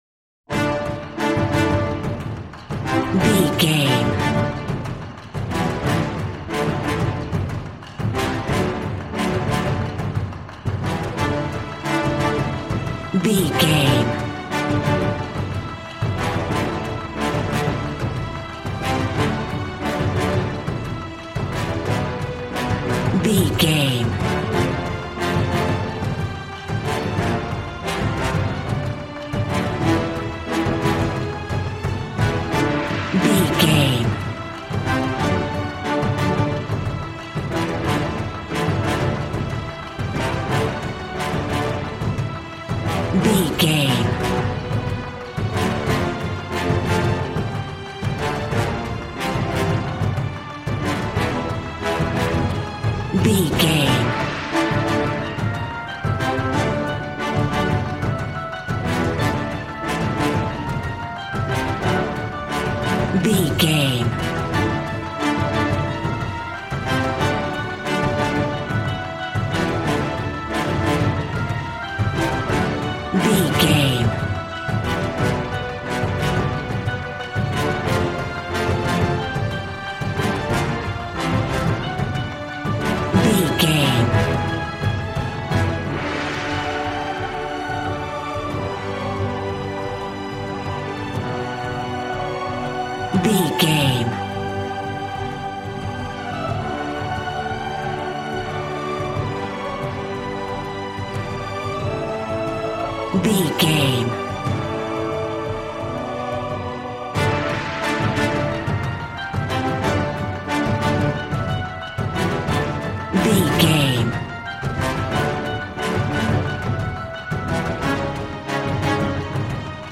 Ionian/Major
regal
cello
double bass